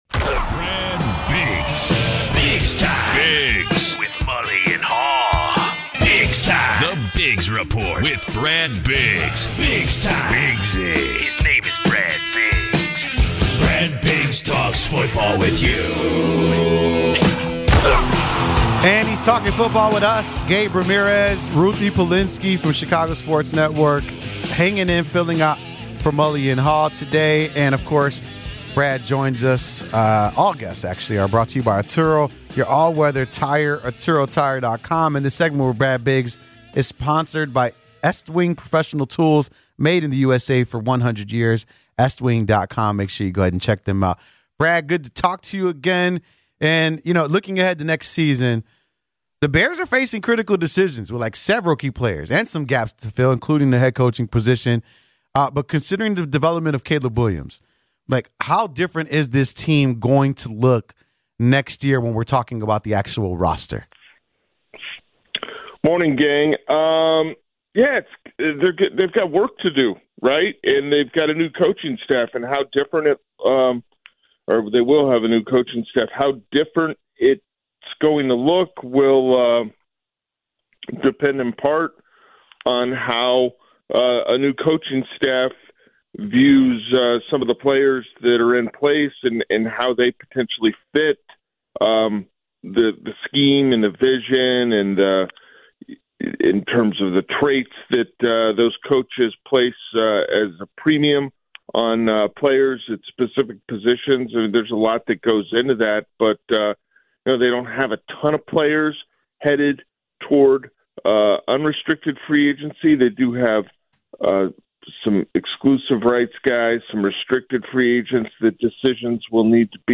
Later, Score football analyst Dave Wannstedt joined the show to preview the Bears-Packers game Sunday.